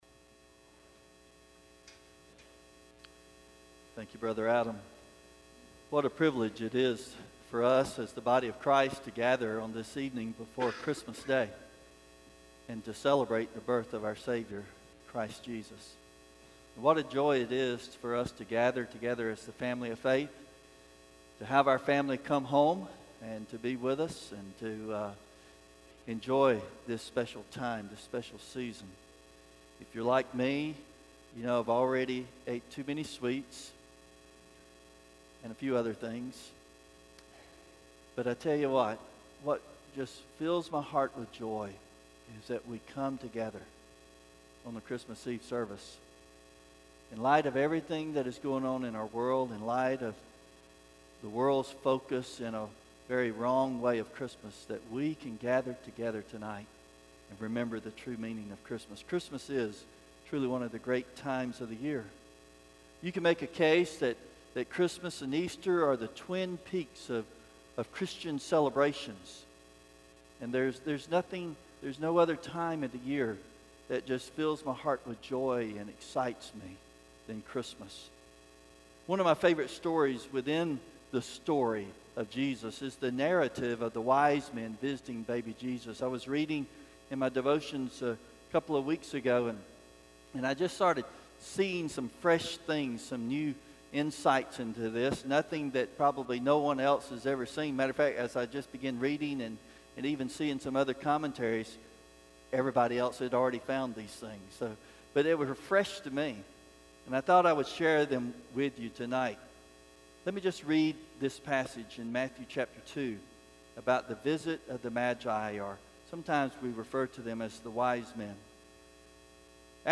Christmas Eve Candlelight Service
Stand Alone Sermons Service Type